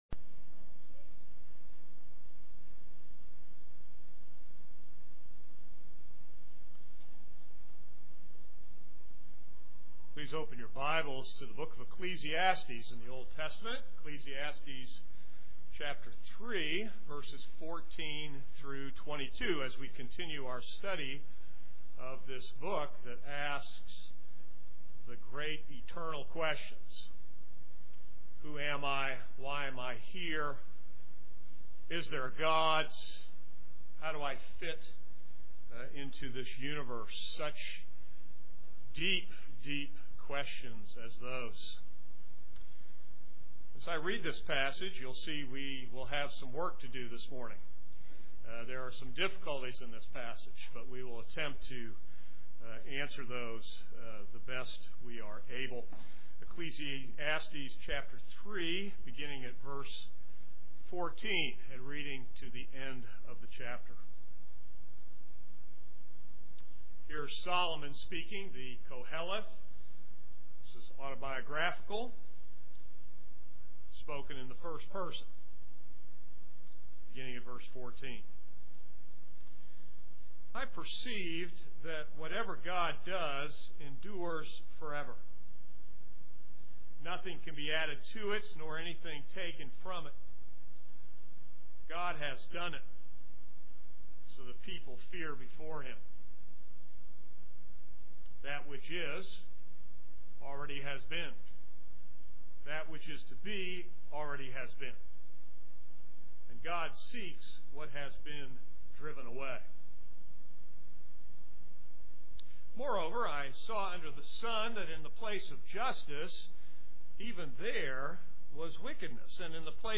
This is a sermon on Ecclesiastes 3:14-25.